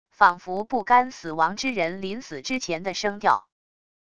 仿佛不甘死亡之人临死之前的声调wav音频